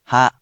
We’re going to show you the character, then you you can click the play button to hear QUIZBO™ sound it out for you.
In romaji, 「は」 is transliterated as「ha」which sounds like 「hahh」which sounds sort of like the ho in 「hot